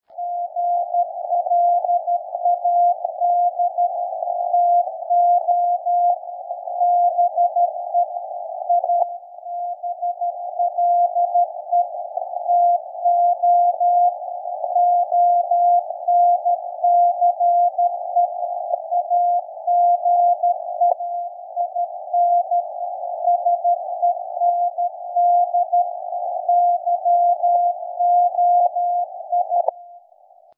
This is an excerpt of the audio received